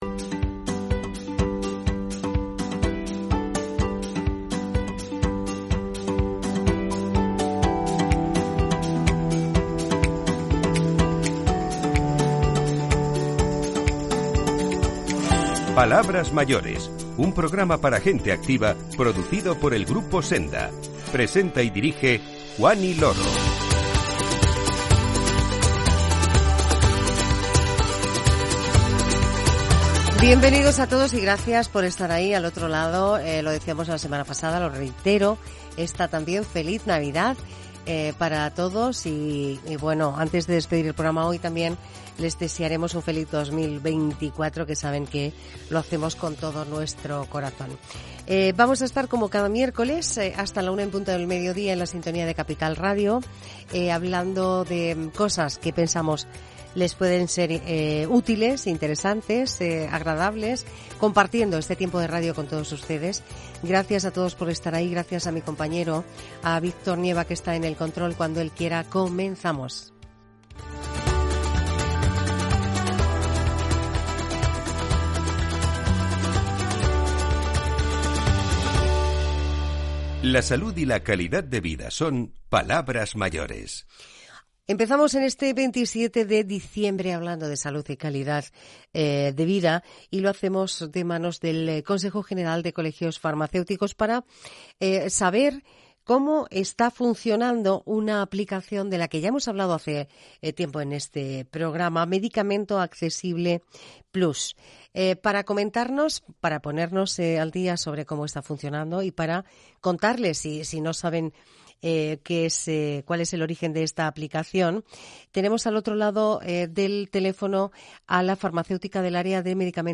Una nueva oportunidad, visita el estudio de Palabras Mayores para recordarnos a todos lo importante que es tener propósitos.